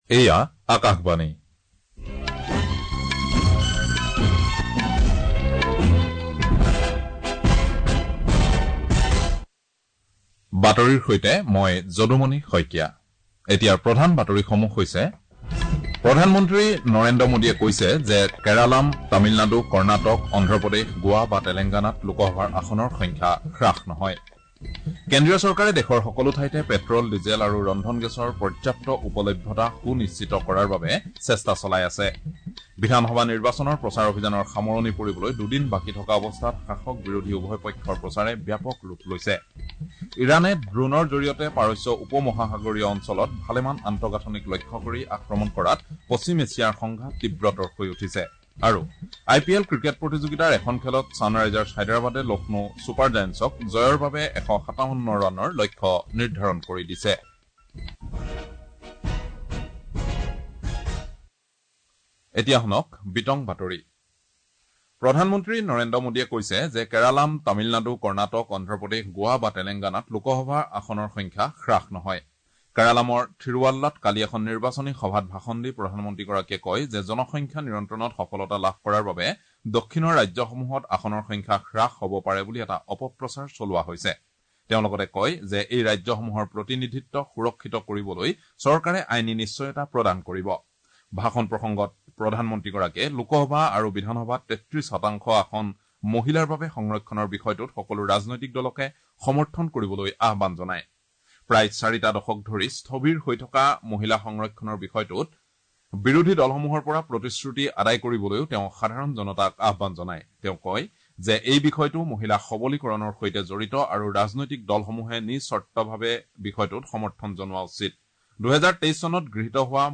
Access Bulletins From Cities